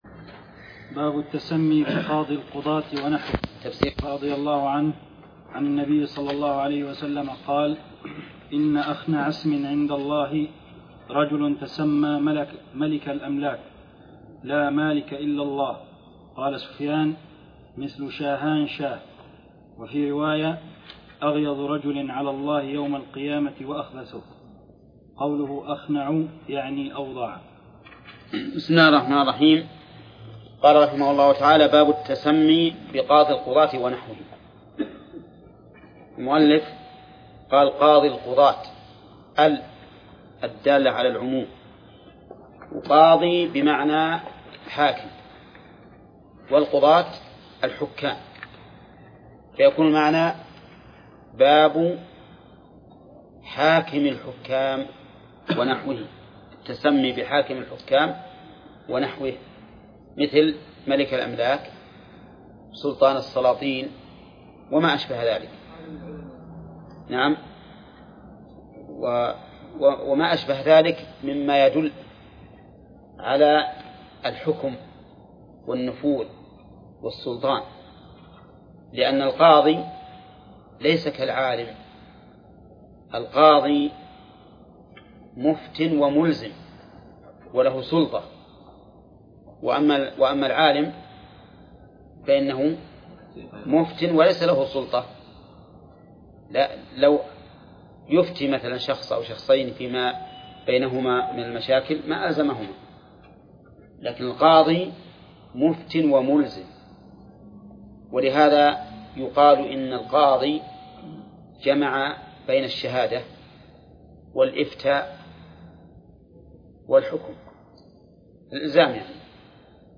درس (41) / المجلد الثاني : من صفحة: (249)، قوله: (باب التسمي بقاضي القضاة ونحوه).، إلى صفحة: (267)، قوله: (باب من هزل بشيء فيه ذكر الله).